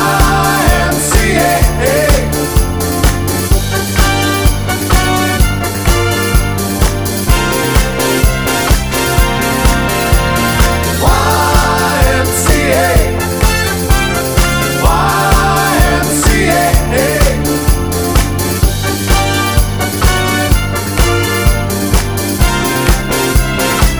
One Semitone Down Disco 3:43 Buy £1.50